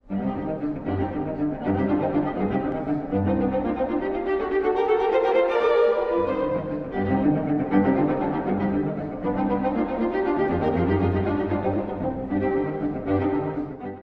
妖精が囁くような音楽です。
ｐで奏でられるスタッカートの連続は、人によって
「かわいい」「妖しい」とさまざまに感じるでしょう。